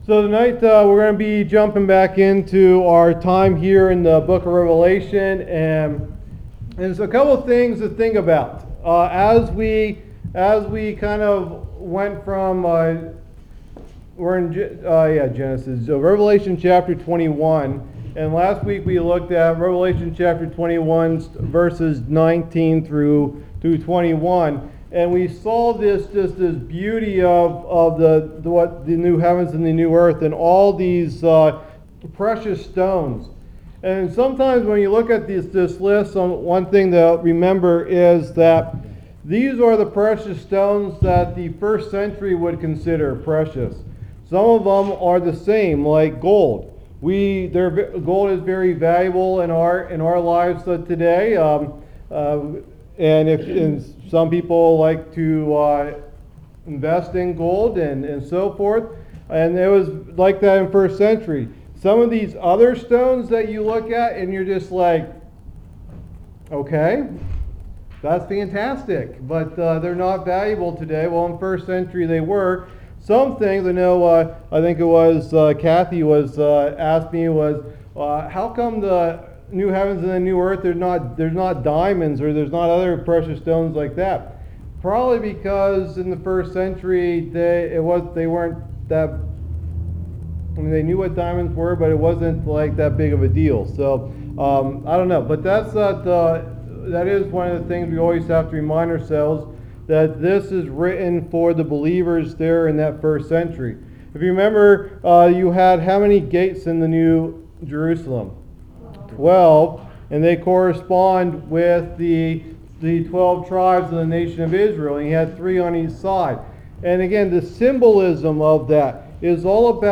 Message #52 in the "Book of Revelation" teaching series